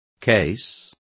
Also find out how pleito is pronounced correctly.